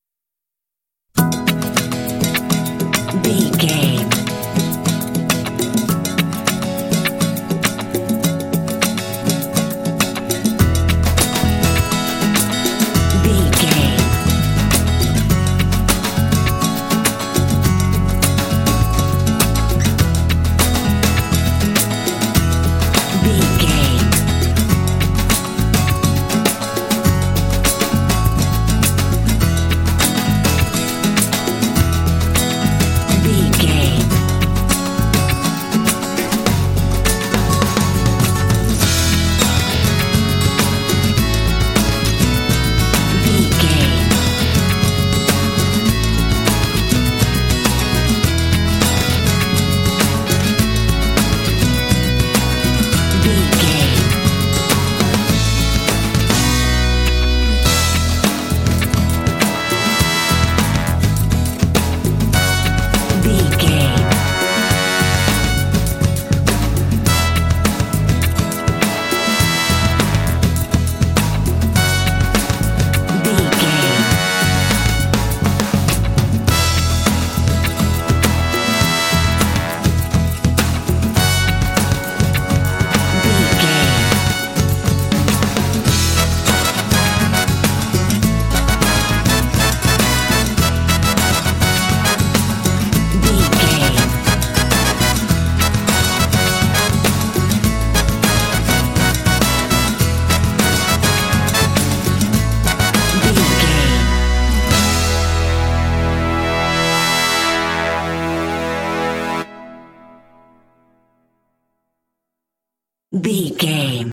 Uplifting
Ionian/Major
happy
bouncy
groovy
acoustic guitar
drums
percussion
bass guitar
electric guitar
brass
folk
quirky
indie
alternative rock